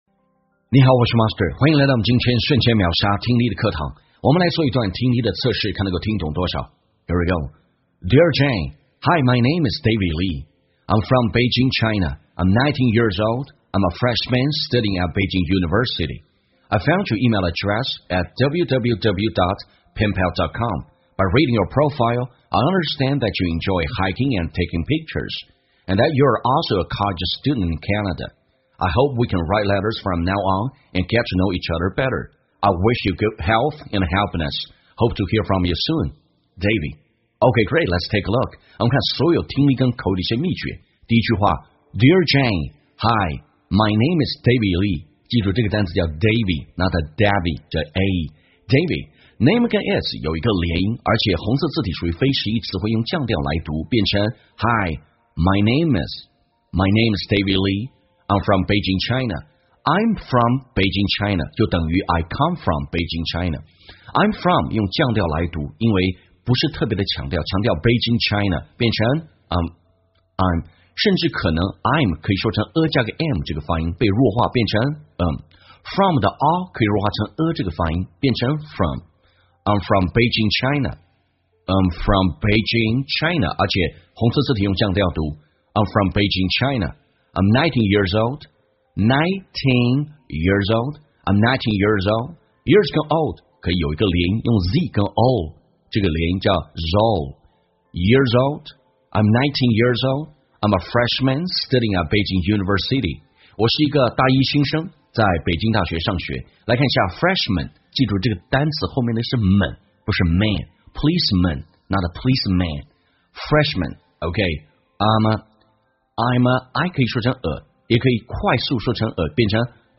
在线英语听力室瞬间秒杀听力 第561期:给笔友的信的听力文件下载,栏目通过对几个小短句的断句停顿、语音语调连读分析，帮你掌握地道英语的发音特点，让你的朗读更流畅自然。